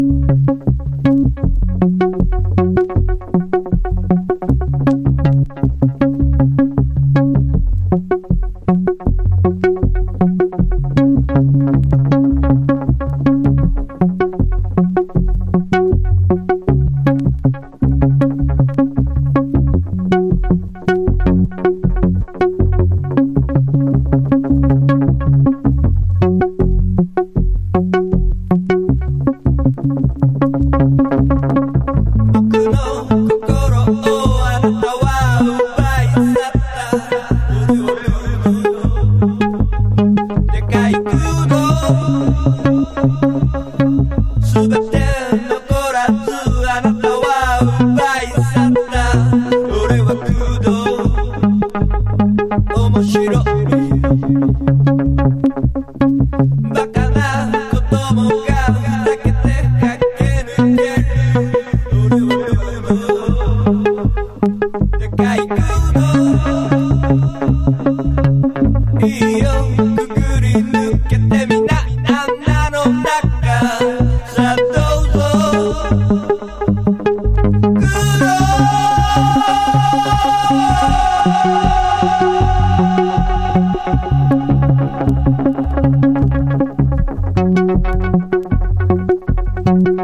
90-20’S ROCK